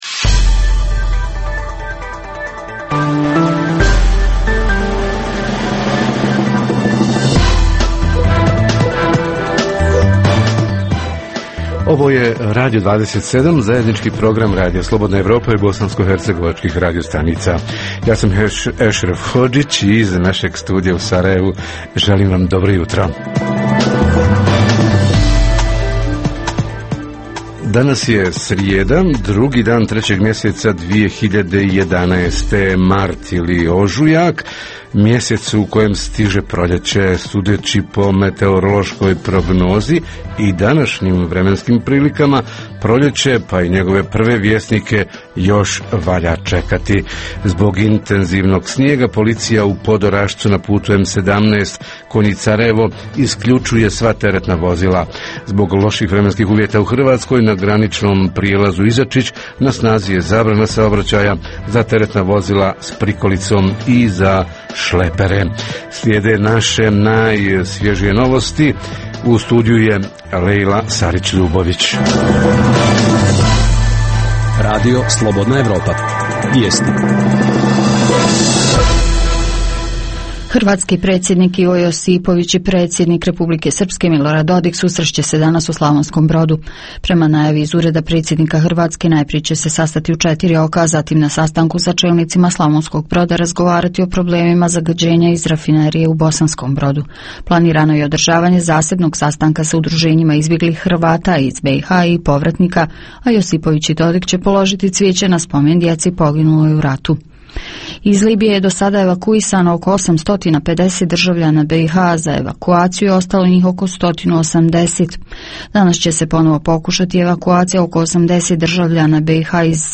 Kako preduprijediti, ili pak podstaći proteste nezadovoljnih – koji su najčešći razlozi zbog kojih se građani žale lokalnoj vlasti, šta s tim u vezi lokalna vlast poduzima, a šta je muguće i potrebno da poduzme? - Reporteri iz cijele BiH javljaju o najaktuelnijim događajima u njihovim sredinama.